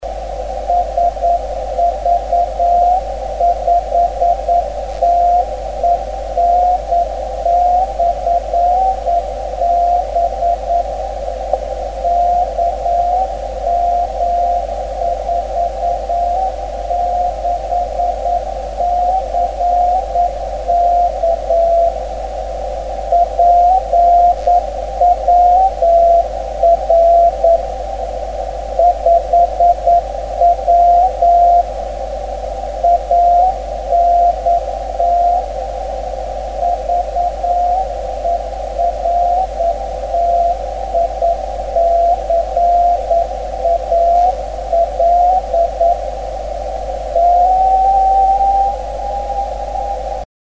10m Baken
Die hier aufgeführten Stationen wurden selbst empfangen.